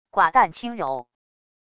寡淡轻柔wav音频